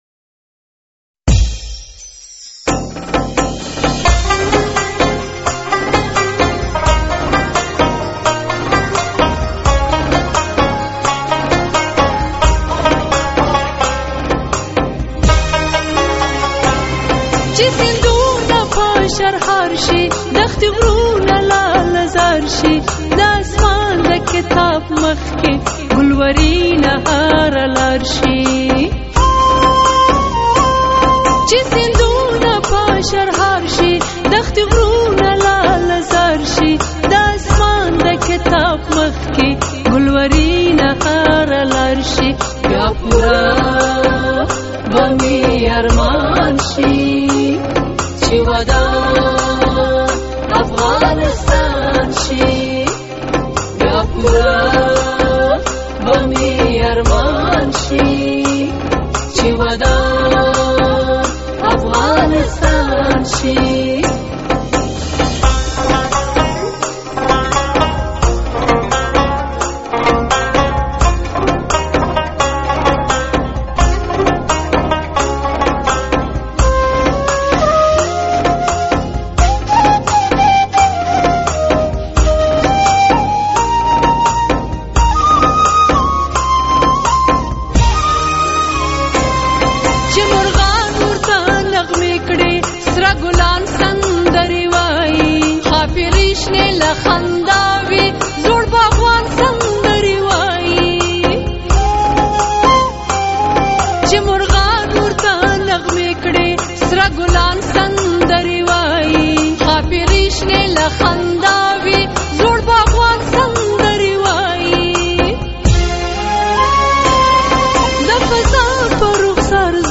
د خوږ ژبې سندرغاړې